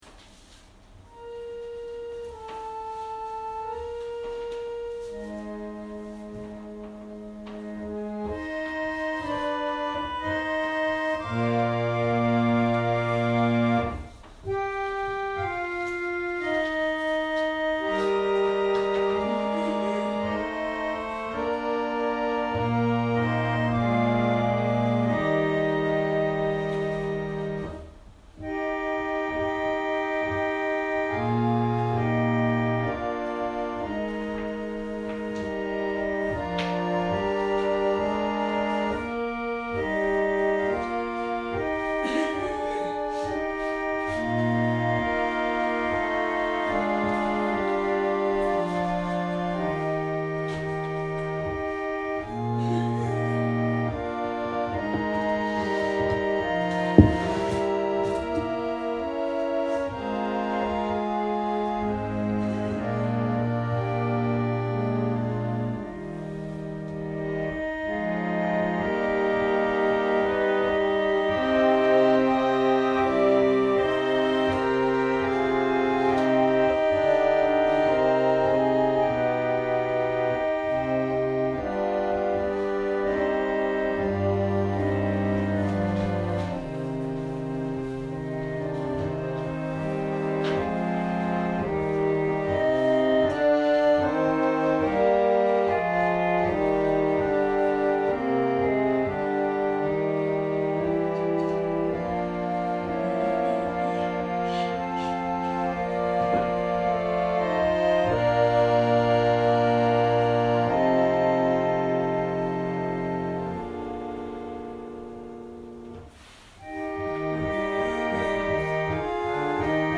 千間台教会。説教アーカイブ。
音声ファイル 礼拝説教を録音した音声ファイルを公開しています。